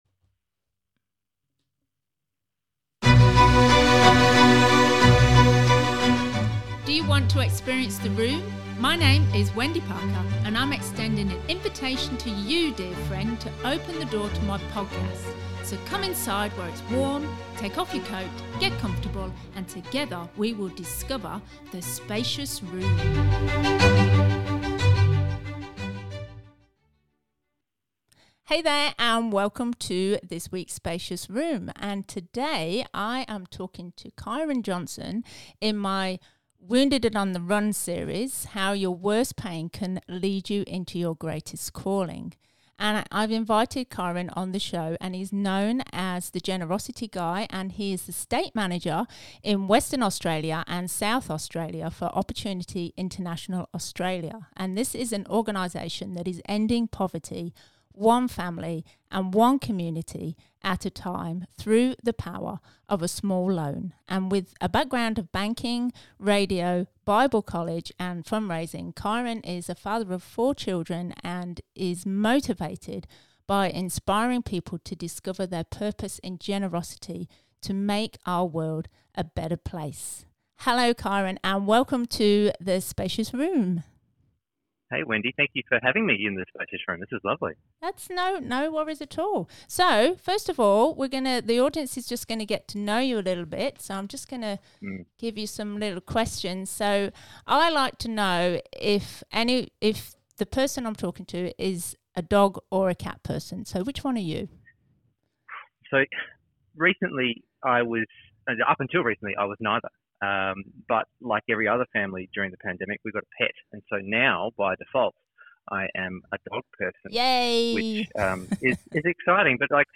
interview-8vjjr.mp3